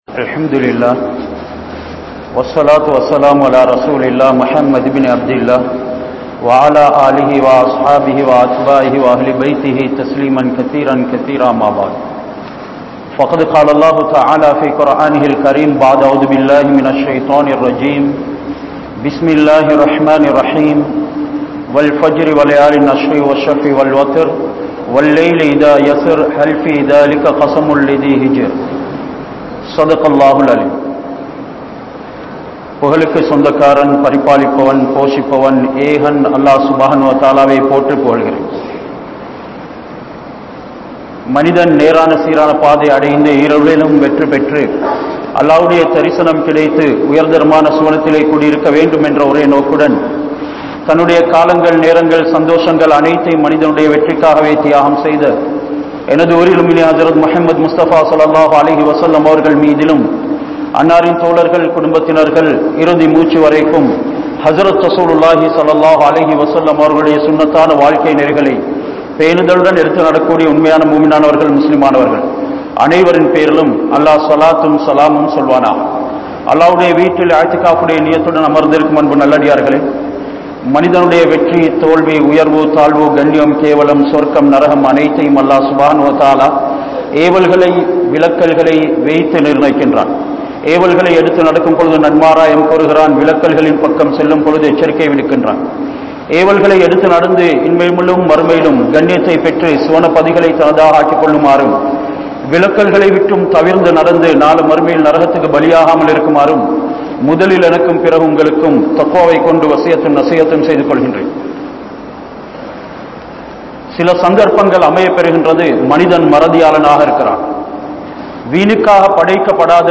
Dhull Hijjavukkuria Amalah (துல்ஹிஜ்ஜாவுக்குரிய அமல்கள்) | Audio Bayans | All Ceylon Muslim Youth Community | Addalaichenai
Katugasthoata Jumua Masjith